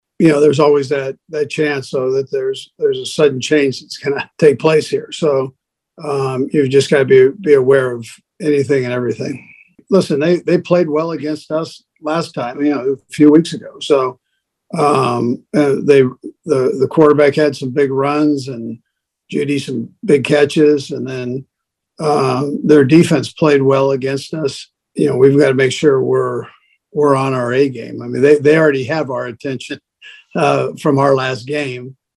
Chiefs Coach Andy Reid says the Broncos have their attention.
12-27-andy-reid.mp3